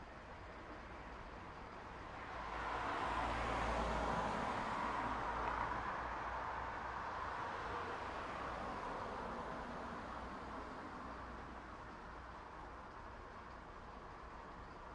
描述：在汽车站等待，右边是柴油货车
Tag: 总线 道路 交通